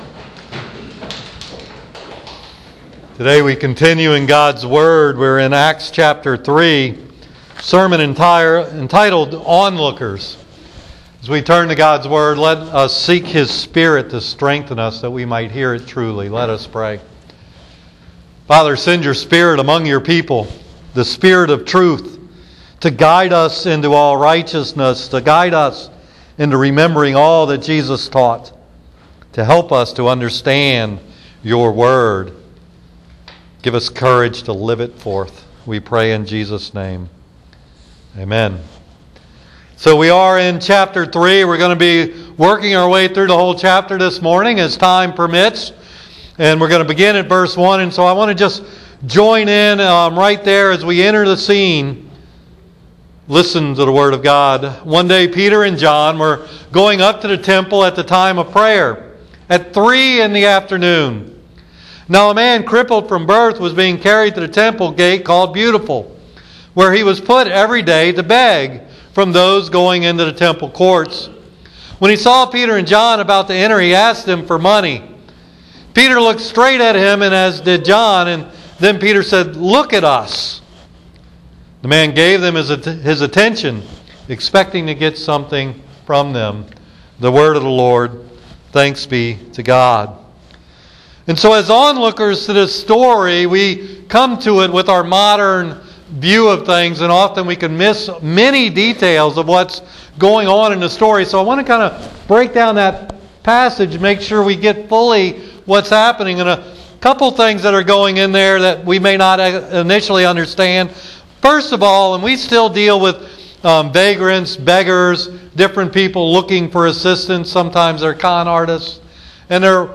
So I made a mistake in this sermon.
Somewhere in my studies I thought I read that the crippled man was a gentile. I made that statement during the oral speaking of my sermon – it was not in my written notes.